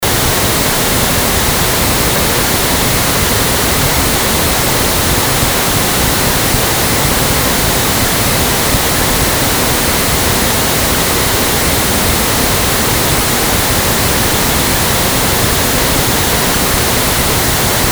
bruit rose
PnoiseNeutrik.mp3